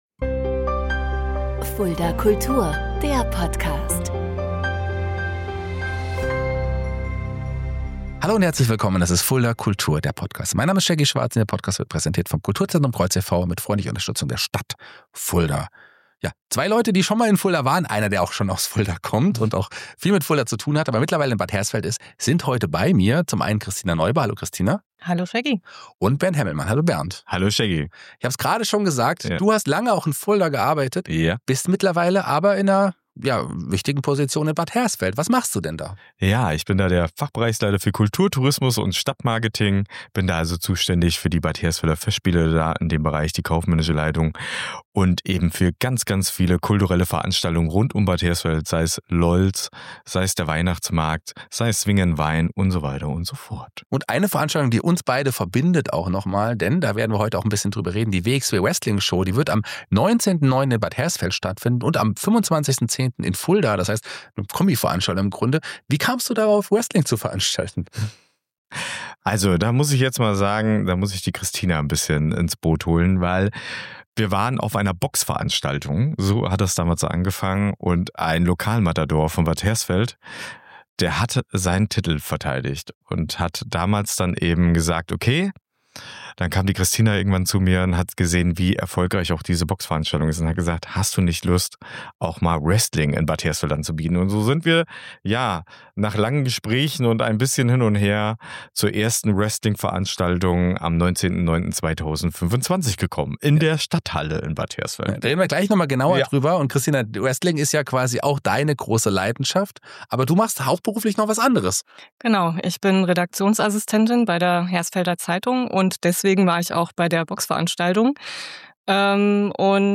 Im Gespräch erzählen sie, was sie an Wrestling begeistert, wie es ist, Hochkultur mit Showkämpfen zu verbinden – und warum live dabei sein alles verändert.